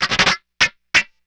SCRAPEAGE 3.wav